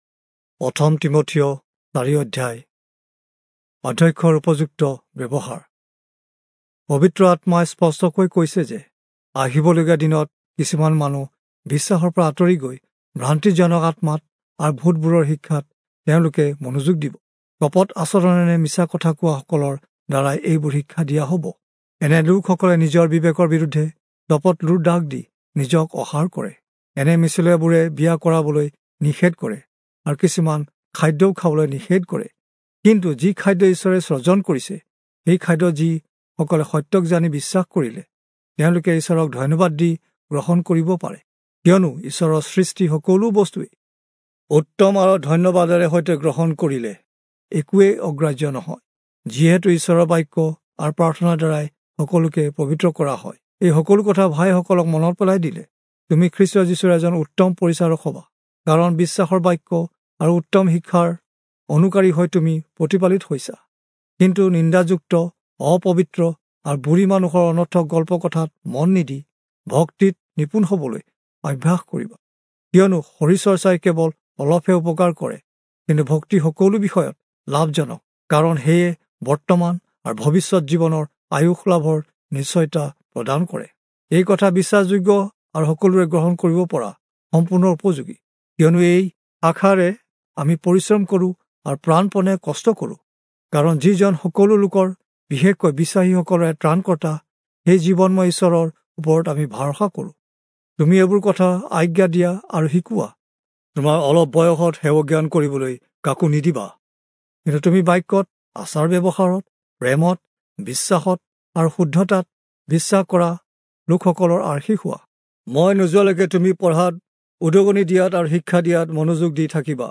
Assamese Audio Bible - 1-Timothy 5 in Irvhi bible version